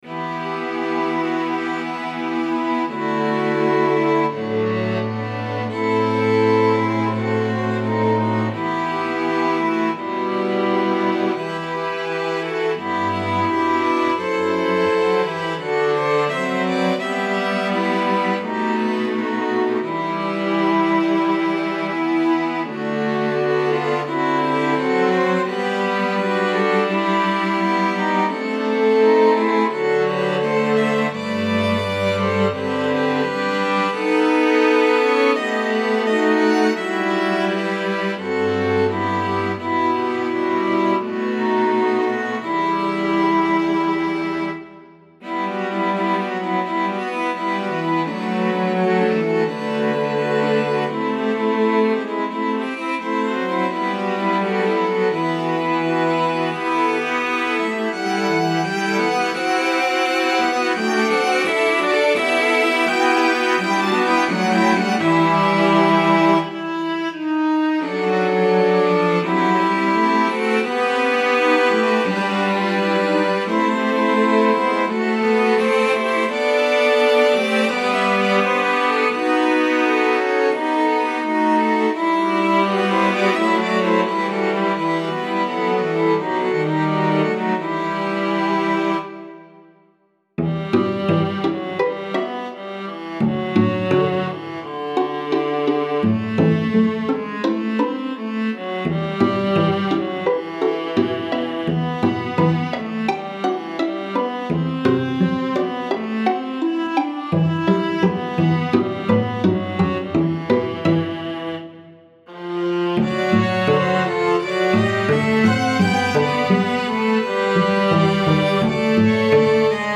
Instrumentation: Violin 1 2, Viola, Cello